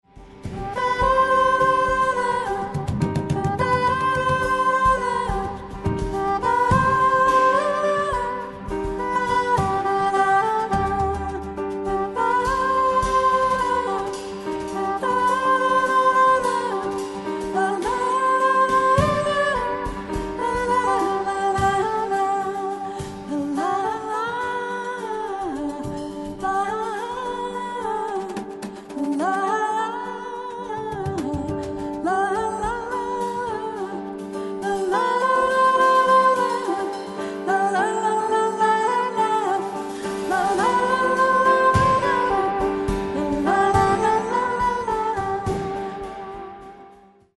Recorded March 2017, ArteSuono Studio, Udine